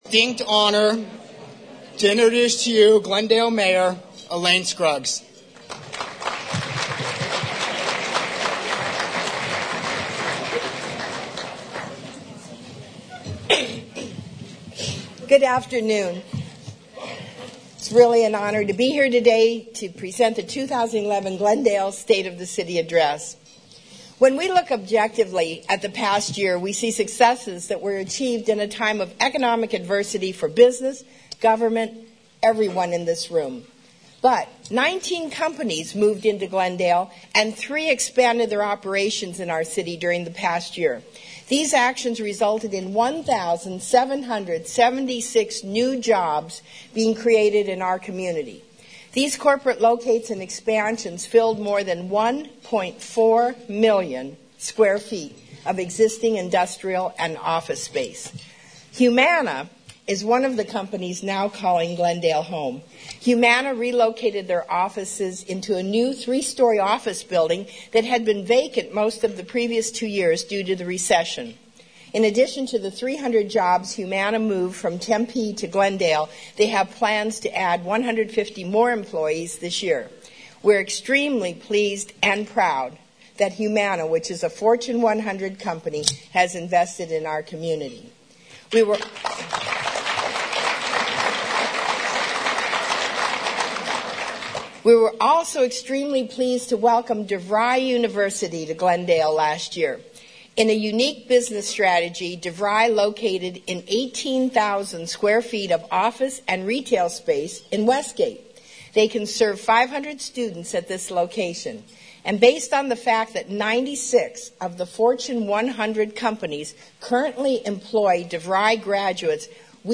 STATE OF THE CITY ADDRESS FEBRUARY 16, 2011 CITY OF GLENDALE, ARIZONA